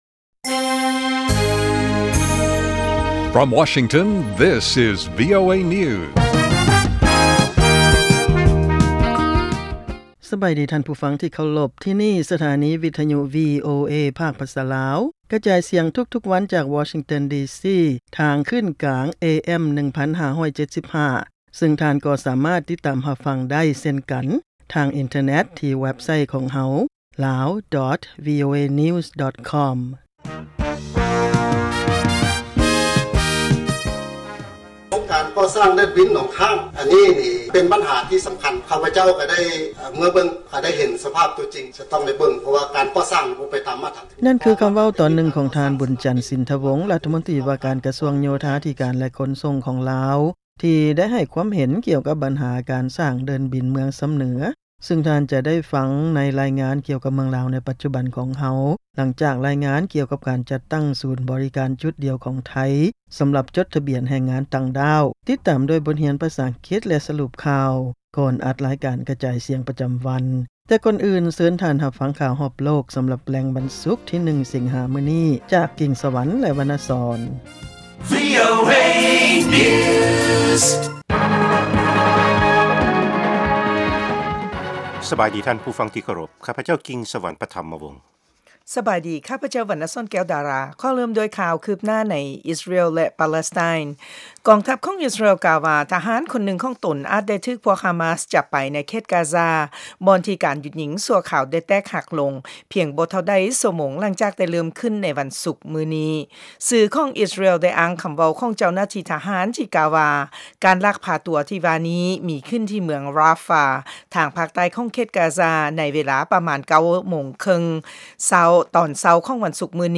ລາຍການກະຈາຍສຽງຂອງວີໂອເອ ລາວ
ວີໂອເອພາກພາສາລາວ ກະຈາຍສຽງທຸກໆວັນ ເປັນເວລາ 30 ນາທີ.